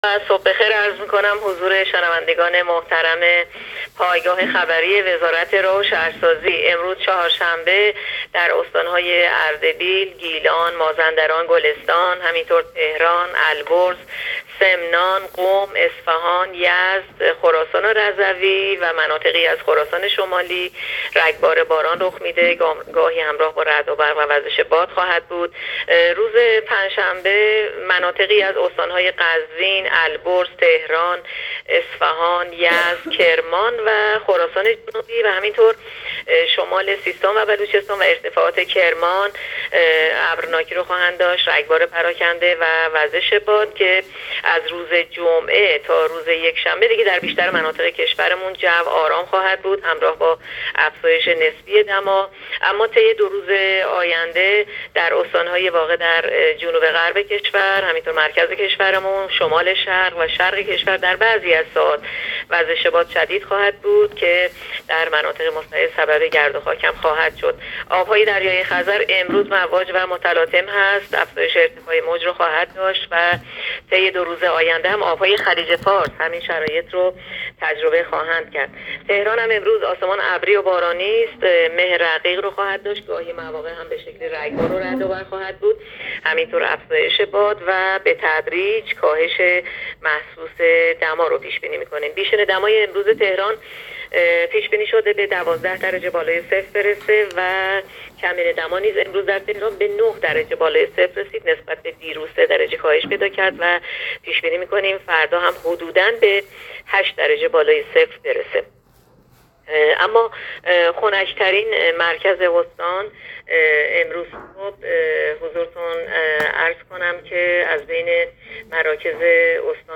گزارش رادیو اینترنتی پایگاه‌ خبری از آخرین وضعیت آب‌وهوای ۲۳ آبان؛